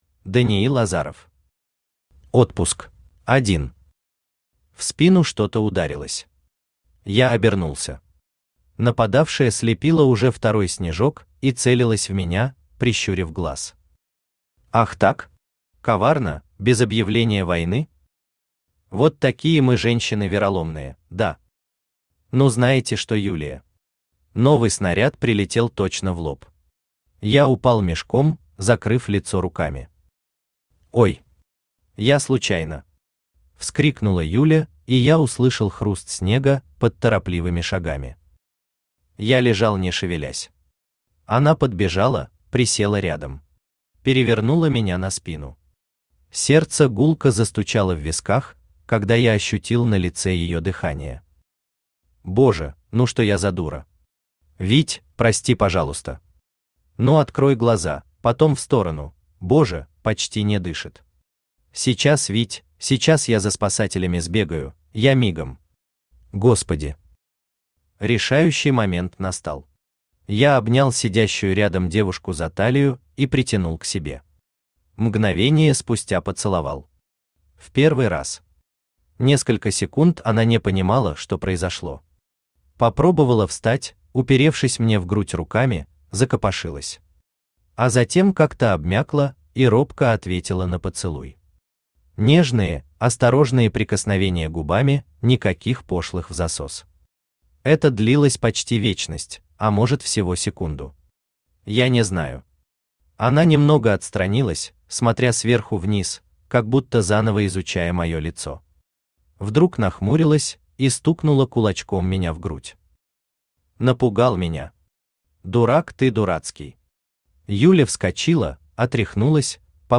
Аудиокнига Отпуск | Библиотека аудиокниг
Aудиокнига Отпуск Автор Даниил Азаров Читает аудиокнигу Авточтец ЛитРес.